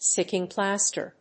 stíck・ing plàster
音節stícking plàster